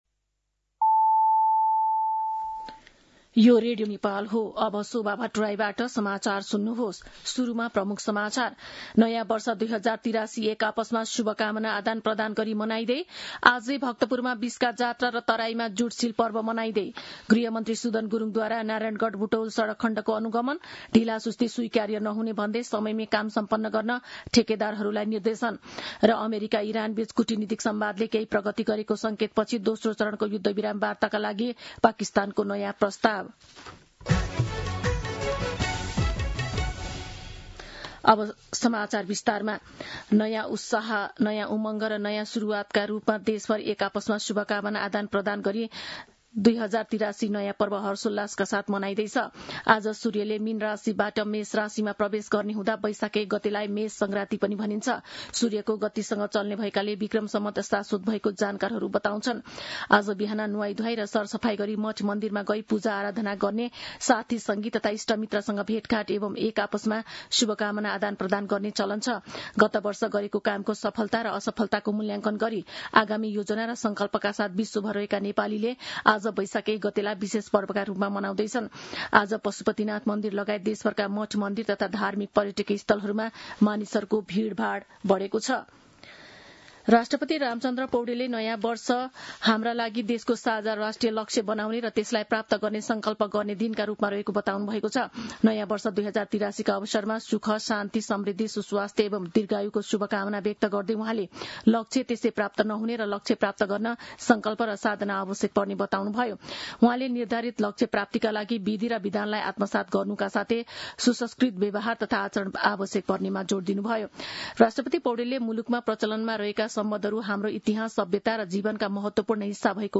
दिउँसो ३ बजेको नेपाली समाचार : १ वैशाख , २०८३